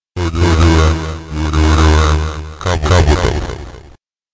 chants some words...
splatterp2_chant.mp3